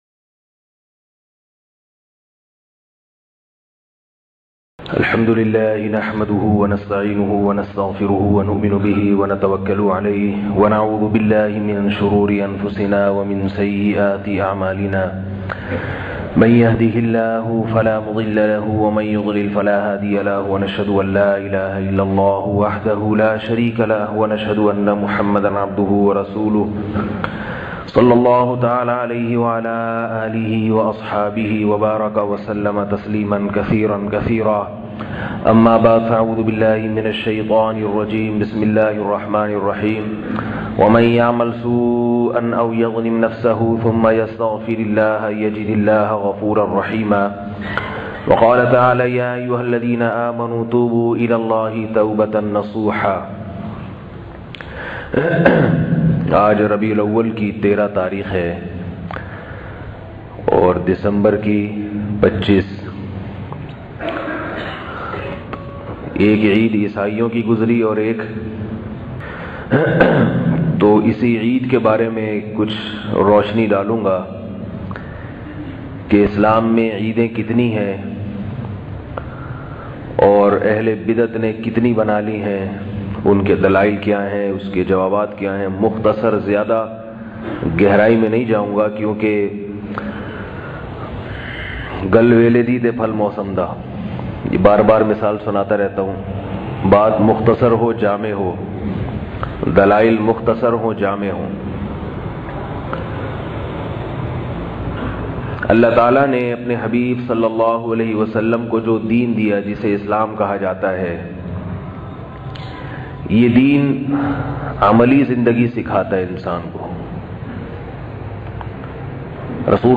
Eid Milad un Nabi kese Manain bayan mp3